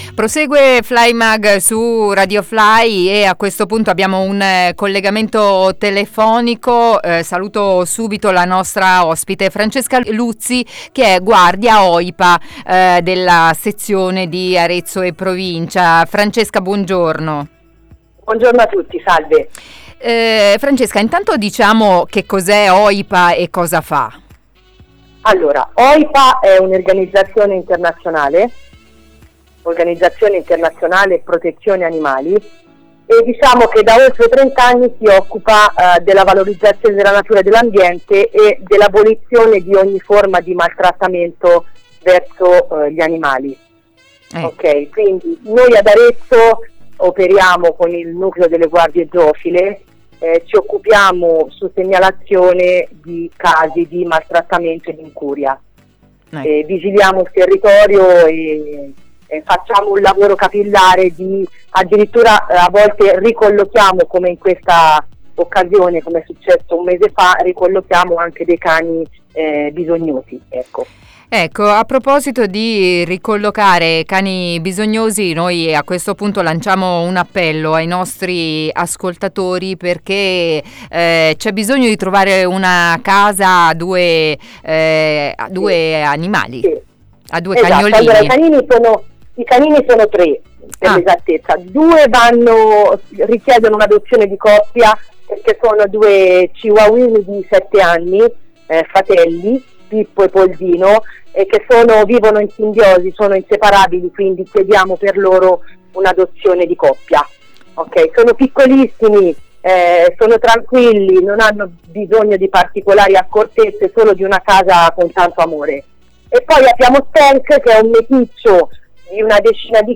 I dettagli nel collegamento telefonico